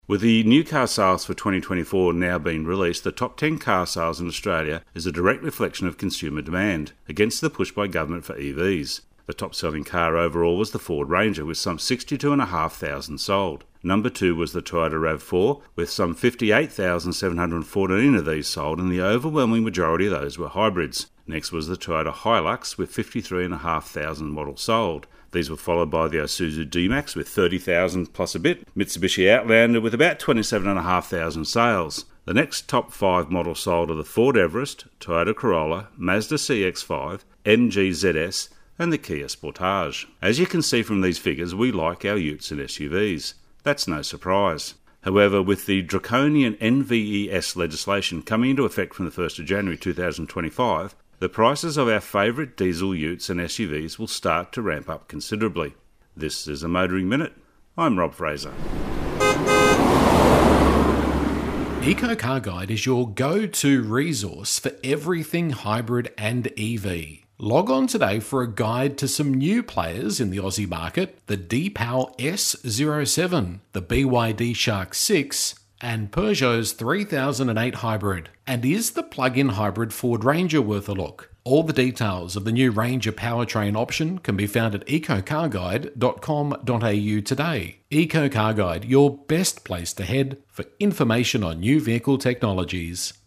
Radio Segments Motoring Minute